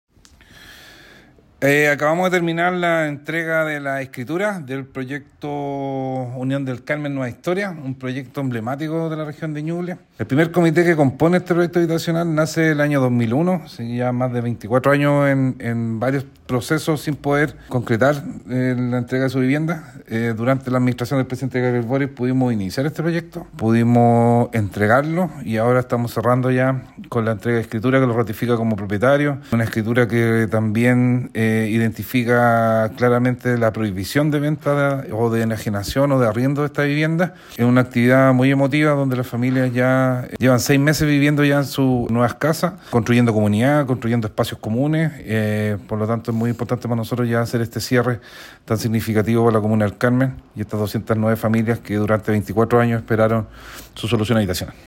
En una emotiva ceremonia realizada en el gimnasio municipal de El Carmen, 209 familias recibieron las escrituras de sus viviendas, marcando el cierre de un largo proceso que se inició hace más de dos décadas.
Seremi-de-Vivienda-Antonio-Marchant.mp3